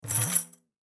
Звуки бубна
Звук Быстро провели пальцами по поверхности